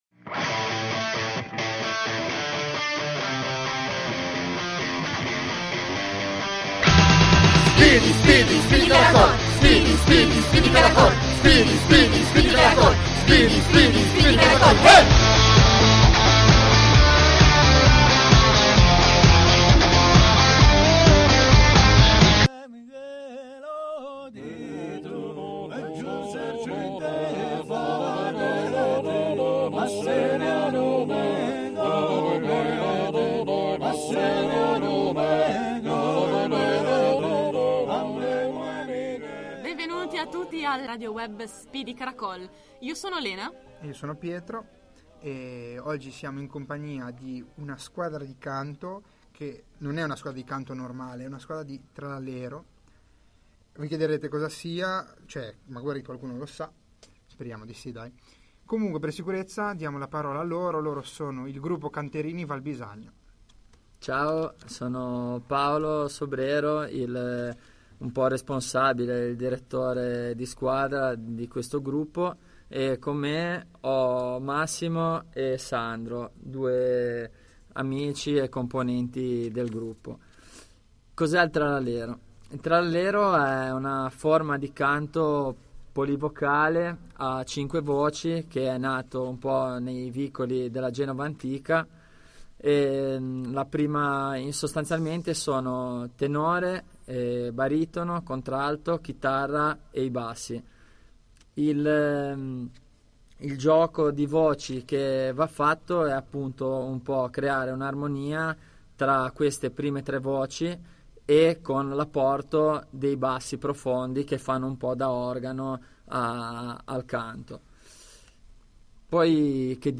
play_circle_filled Speedy Caracol - Puntata n°3 ospiti il gruppo Canterini della Valbisagno Radioweb C.A.G. di Sestri Levante Terza puntata di Speedy Caracol: questa volta si parla di musica tradizionale genovese con il gruppo Canterini della Valbisagno.